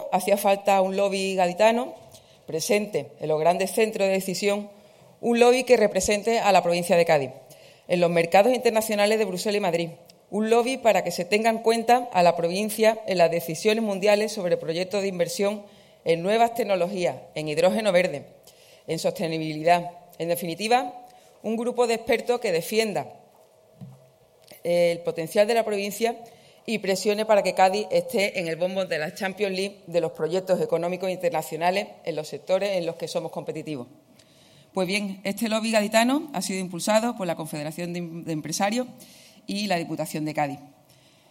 La presidenta ha participado en la presentación de Cádiz Investment Hub, una iniciativa de la CEC financiada por la Diputación